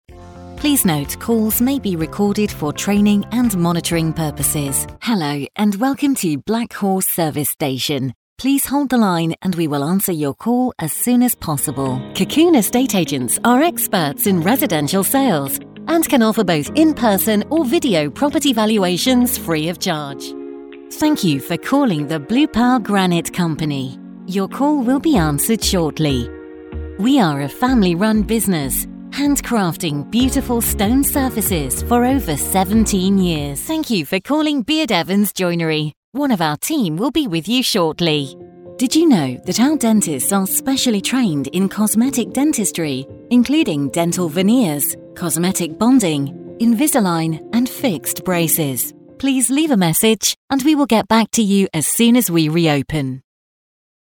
IVR
Meine Stimme wird als warm mit sanfter Würde beschrieben – ich liebe es, tiefgründige und modulierte Erzählungen vorzutragen – obwohl ich definitiv auch etwas mehr Abwechslung in lustige und spritzigere Lesungen bringen kann.
Neumann TLM103 Kondensatormikrofon
Heimstudio mit speziell gebauter schwebender Isolationskabine